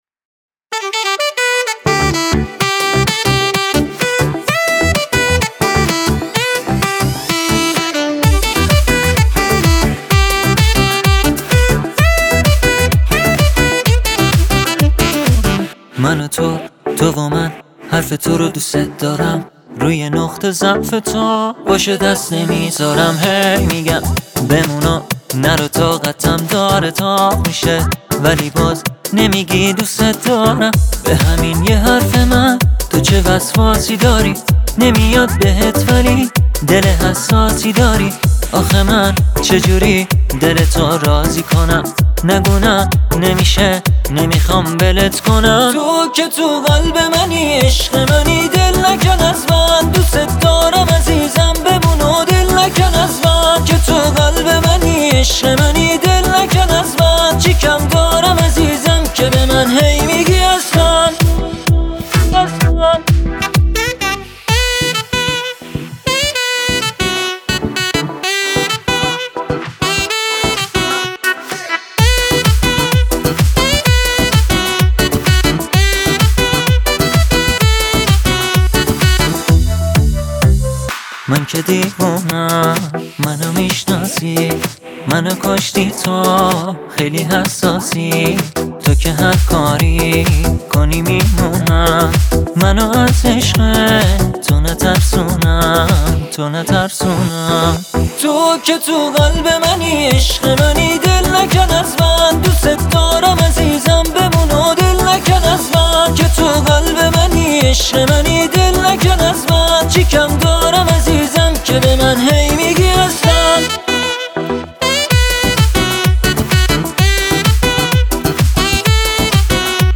بشنوید آهنگ زیبای و احساسی